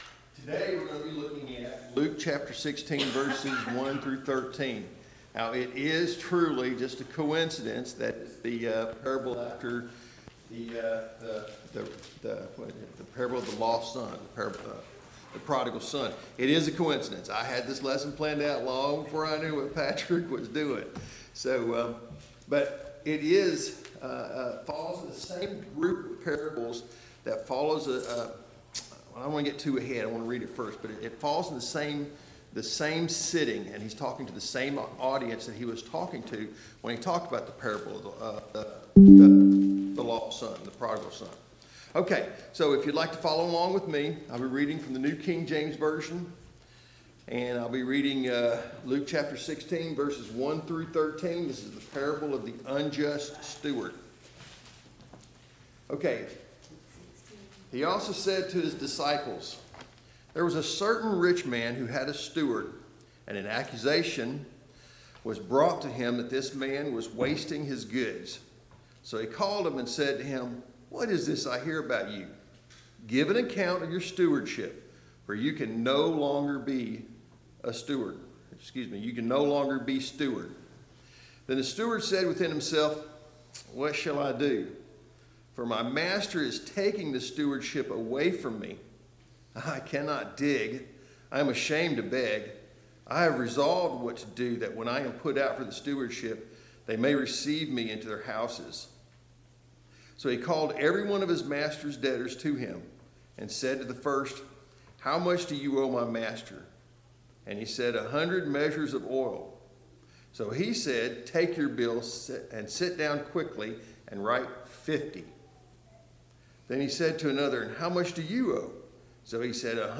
Sunday Morning Bible Class « 1.